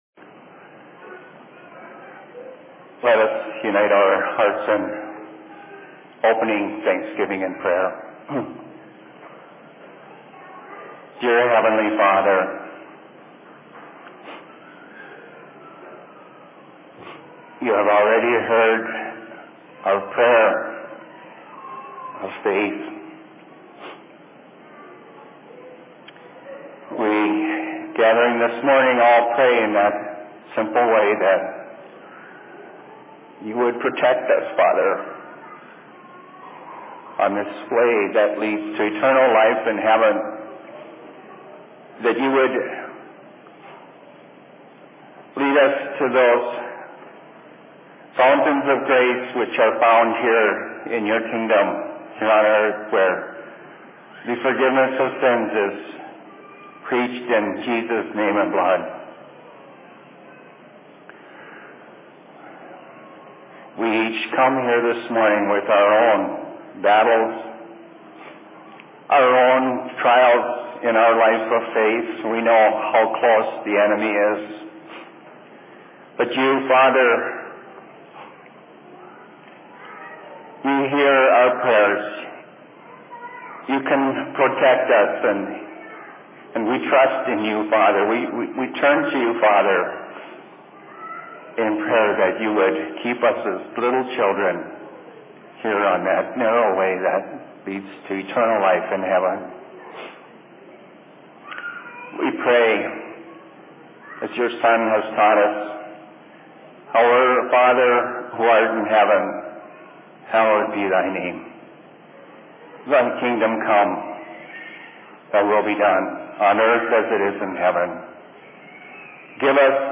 Sermon in Minneapolis 20.07.2014
Location: LLC Minneapolis